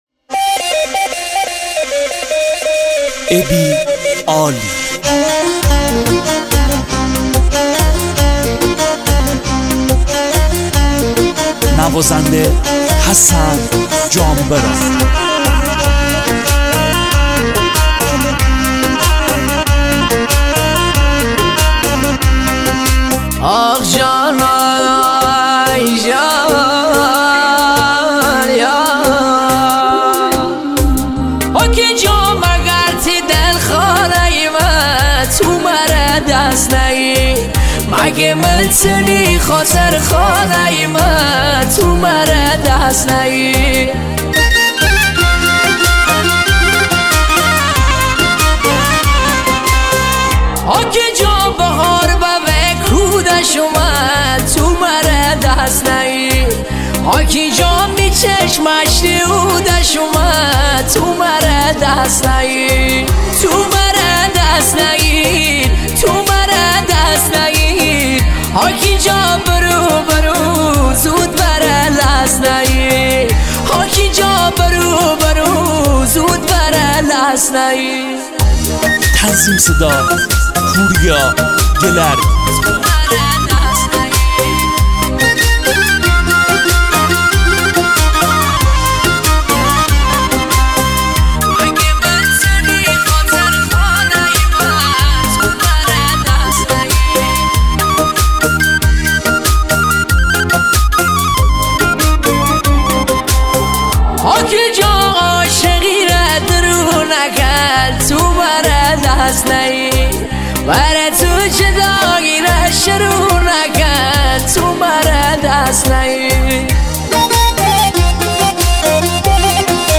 آهنگ شمالی